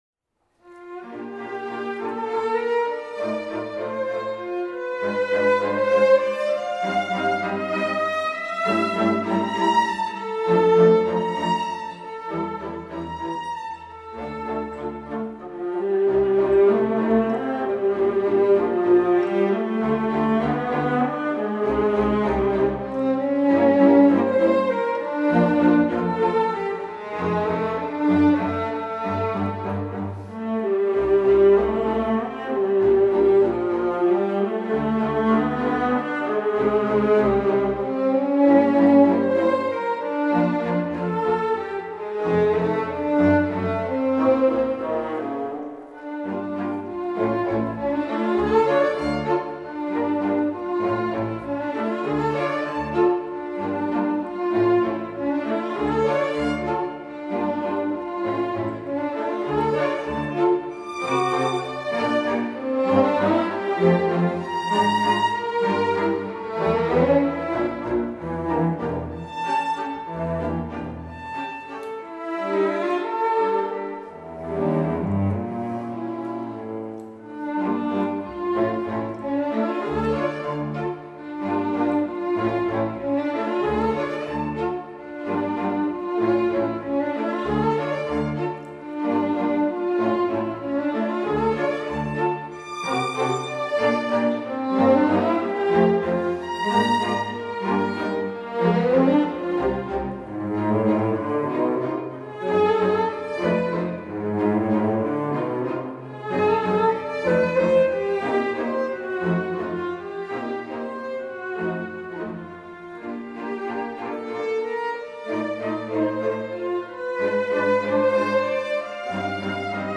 Μουσικά Σύνολα της ΕΡΤ: Τσαϊκόφσκυ – Χριστός Ανέστη! Μουσικοί της Εθνικής Συμφωνικής Ορχήστρας της ΕΡΤ